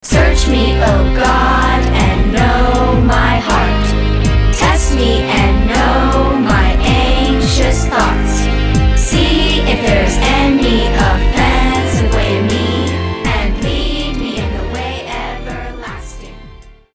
and games for children's choirs.